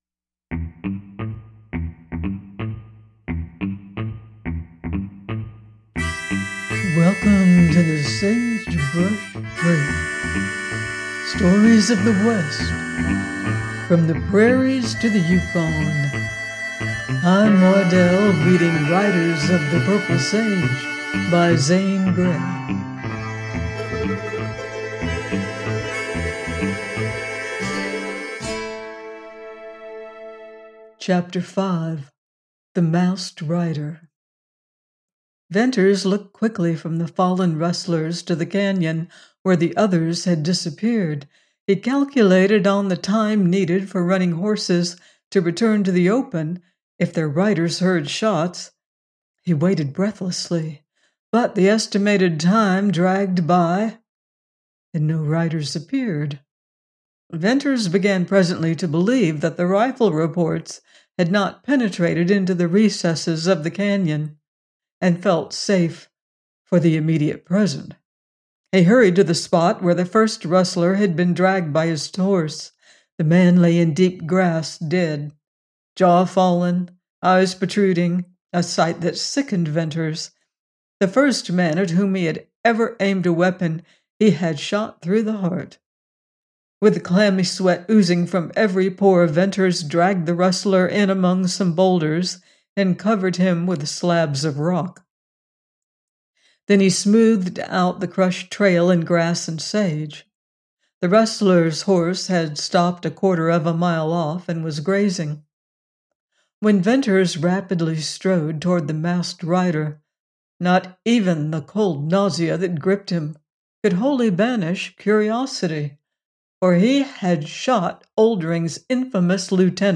Riders Of The Purple Sage – Ch 5: by Zane Grey - audiobook